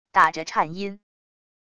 打着颤音wav音频